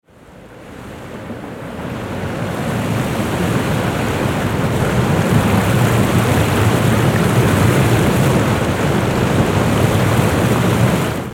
دانلود صدای آبشار 5 از ساعد نیوز با لینک مستقیم و کیفیت بالا
جلوه های صوتی